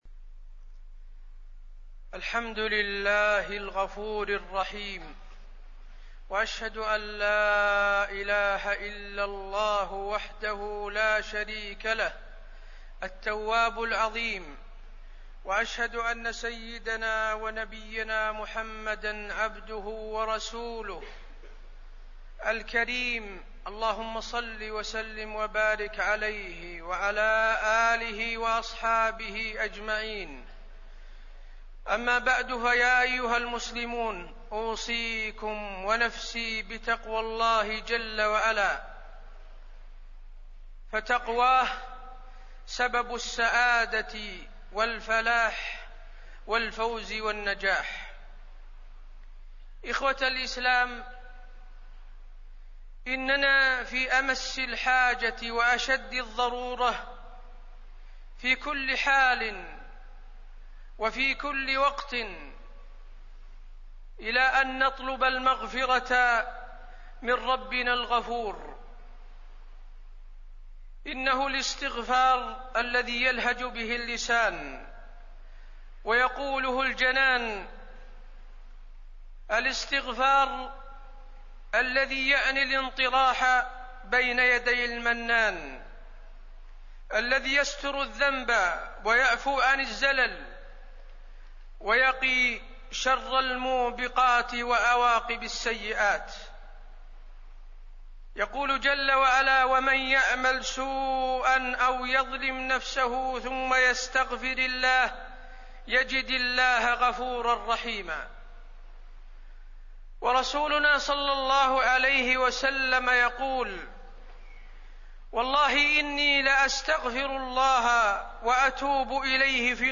تاريخ النشر ١٨ شعبان ١٤٣١ هـ المكان: المسجد النبوي الشيخ: فضيلة الشيخ د. حسين بن عبدالعزيز آل الشيخ فضيلة الشيخ د. حسين بن عبدالعزيز آل الشيخ الاستغفار